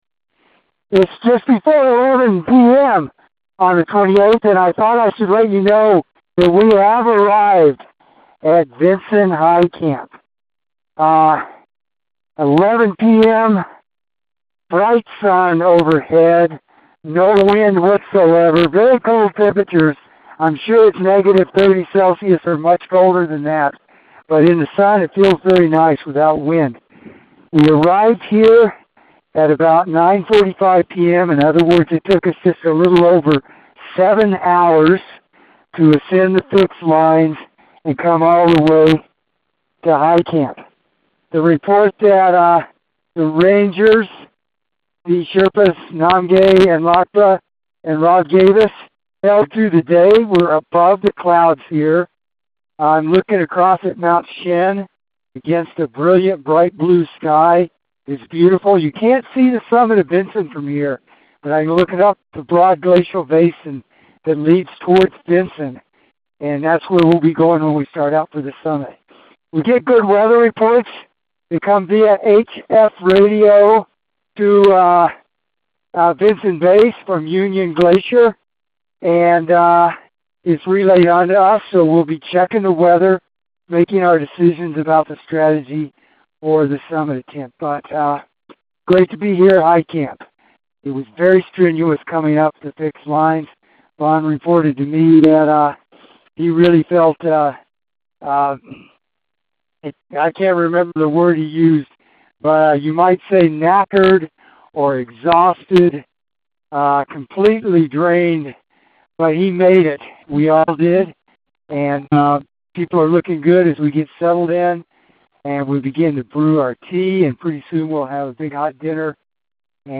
Expedition Dispatch